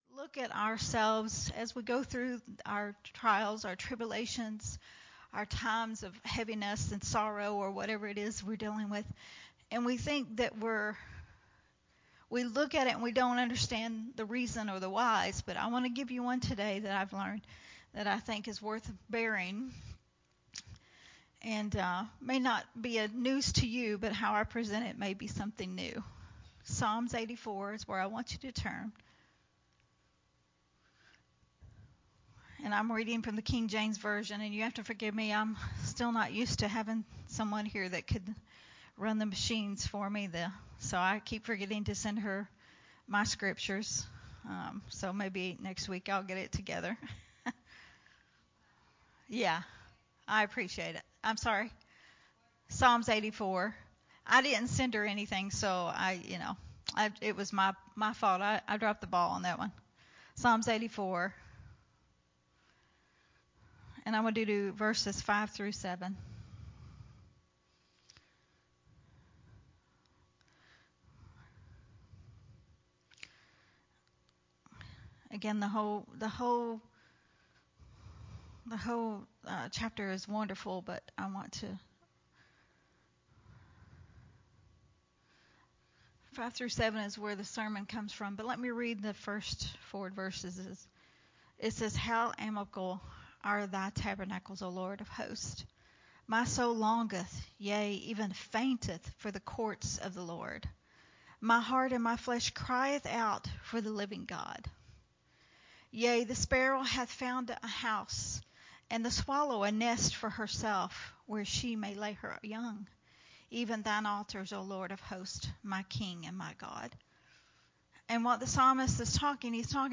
Sunday Morning Refreshing